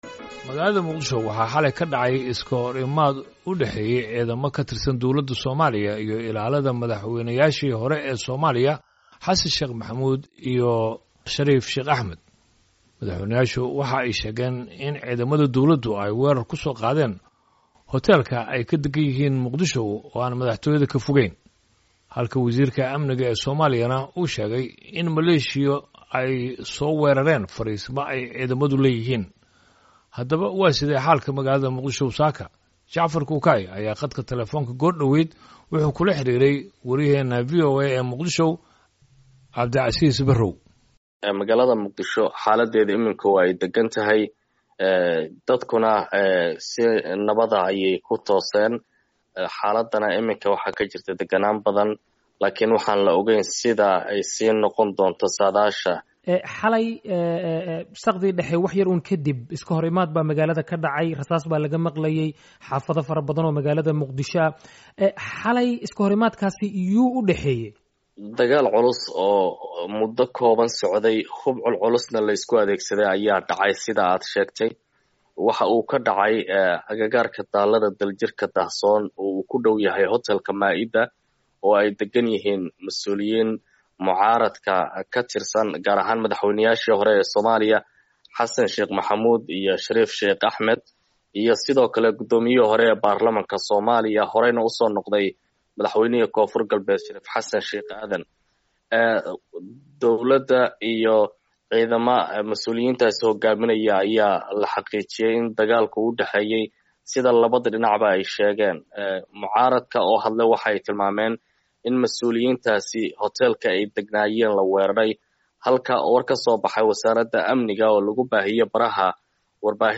Khadka Talefoonka